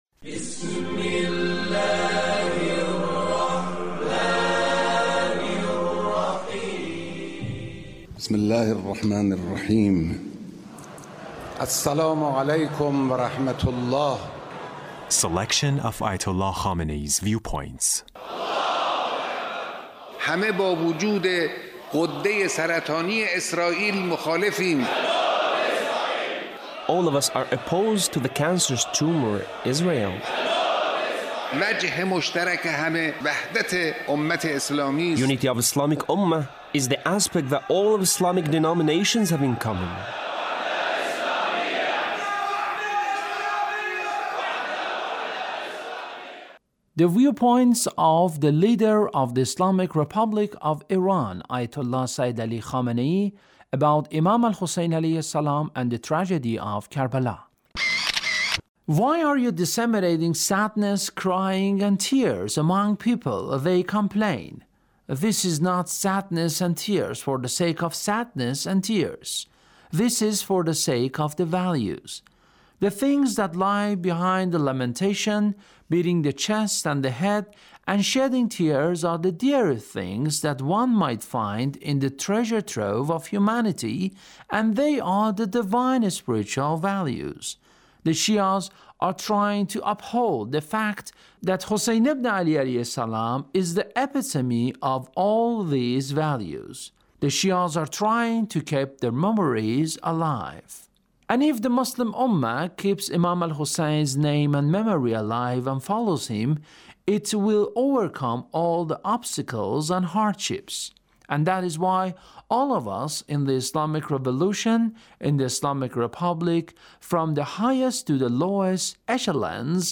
Leader's Speech (1782)